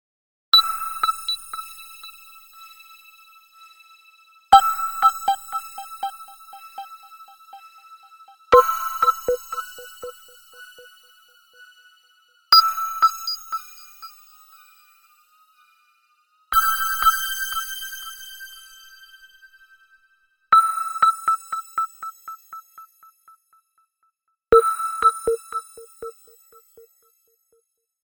MP3 Ringtone